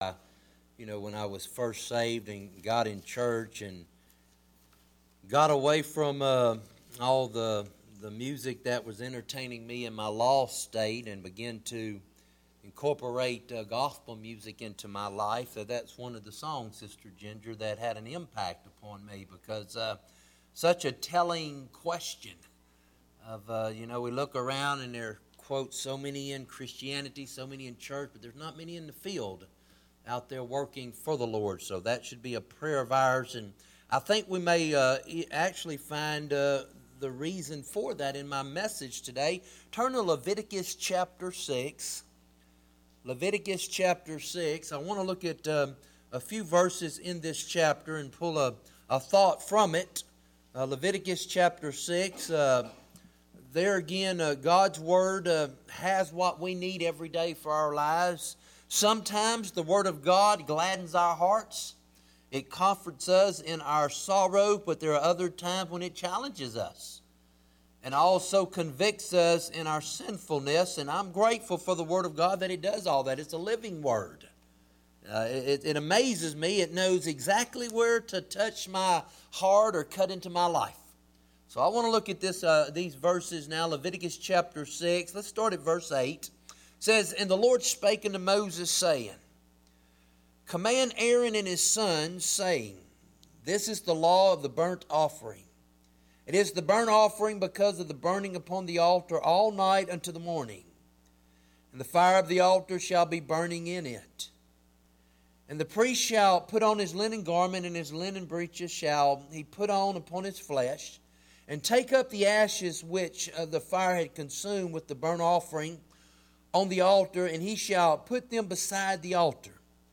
Bible Text: Leviticus 6:8-13 | Preacher